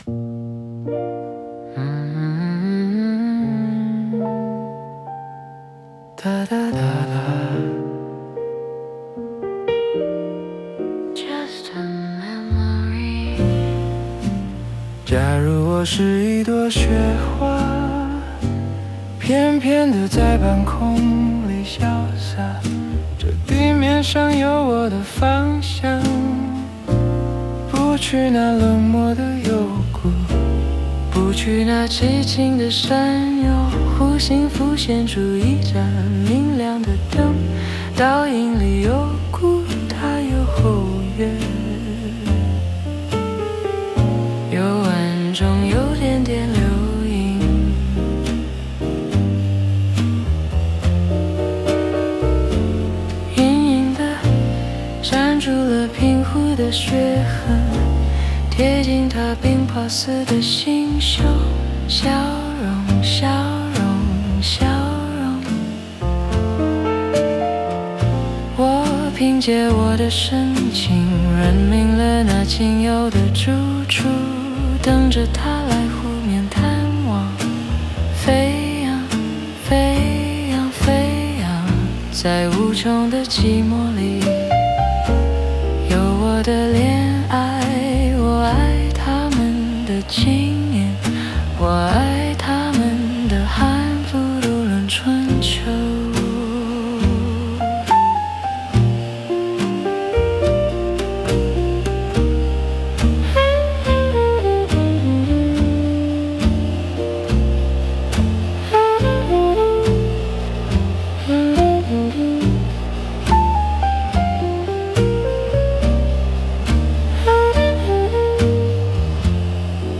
Cool Jazz Ballad｜鋼琴三重奏・60s 類比聲景
本作以鋼琴三重奏為骨架，採慢速、鬆弛的搖擺感，讓旋律自然呼吸。
編制限制為鋼琴三重奏（可加極薄弦樂襯底），刷鼓保持低存在感；混音重點在類比磁帶飽和與中低頻溫度。
slow｜relaxed swing、自由呼吸
直立鋼琴,原聲貝斯,刷鼓,（少量）弦樂襯底